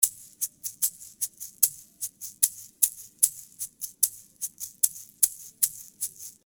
75 BPM shaker (4 variations)
4 loops of egg shaker playing in 75 bpm.